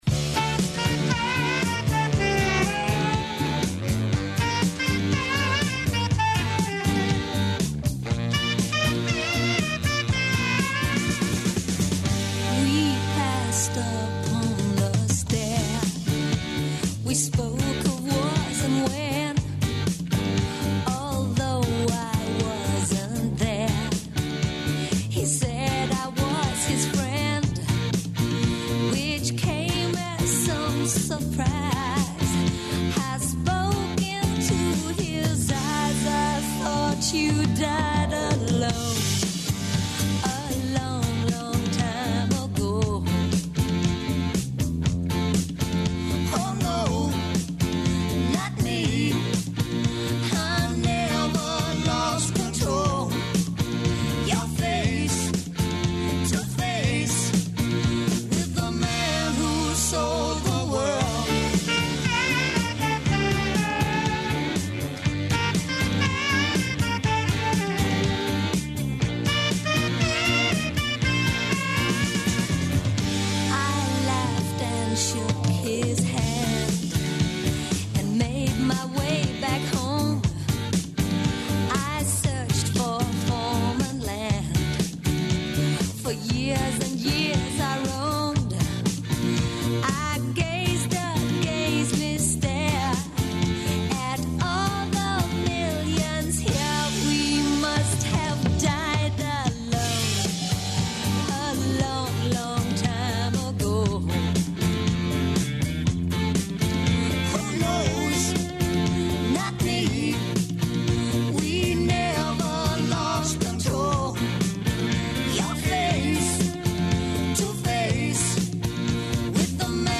У данашњој рубрици КАД САМ БИО СТУДЕНТ, Дарко Коцјан вам открива шта је метларење, спорт који је био популаран у време његовог студирања.